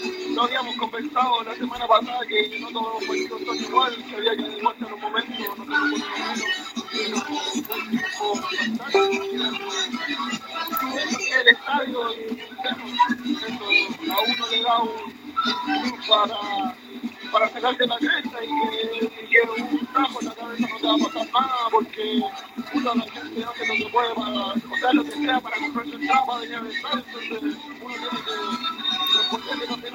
Tras el partido, enfrentó los micrófonos de la Onda Deportiva de Radio Sago y contó detalles de ese especial momento de la ejecución.